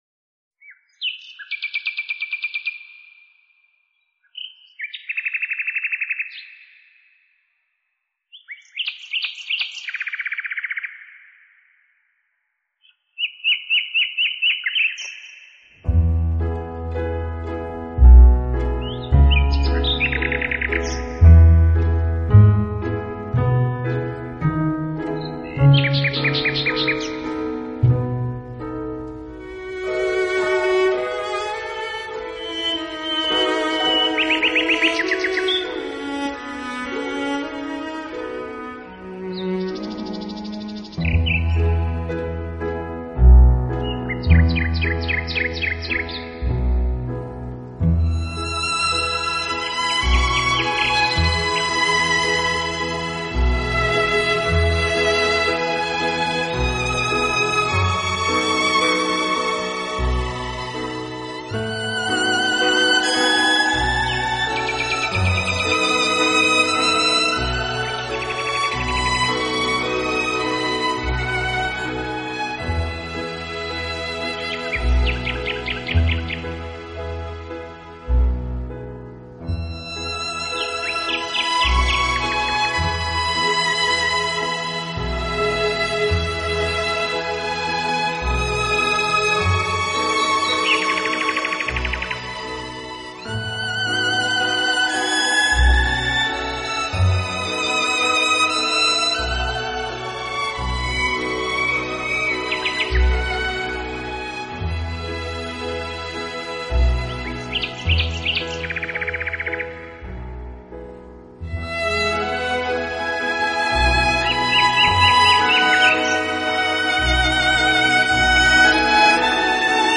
【小提琴专辑】
以演奏曲调清新、旋律优美的音乐而盛名。